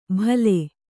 ♪ bhale